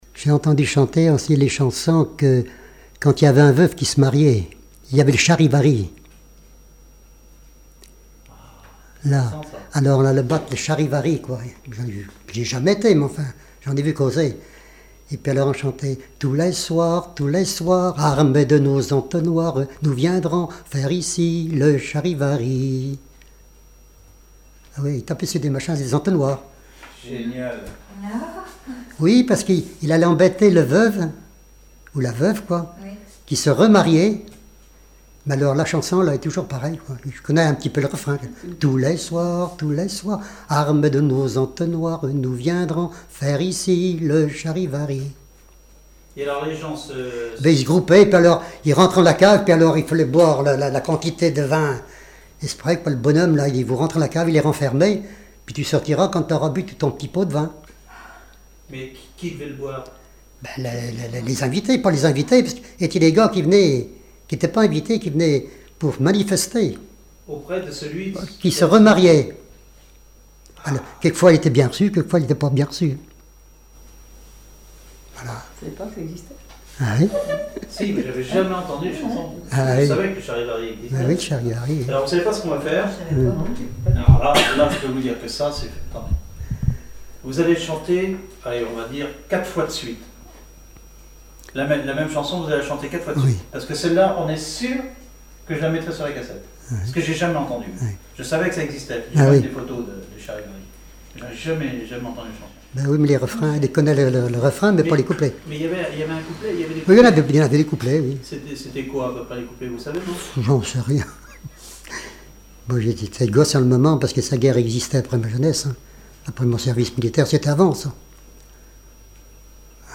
Mémoires et Patrimoines vivants - RaddO est une base de données d'archives iconographiques et sonores.
Enquête Arexcpo en Vendée-Association Joyeux Vendéens
Catégorie Témoignage